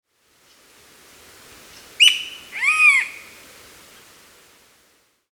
Piaya cayana
Nome em Inglês: Squirrel Cuckoo
Aprecie o canto do
Alma-de-gato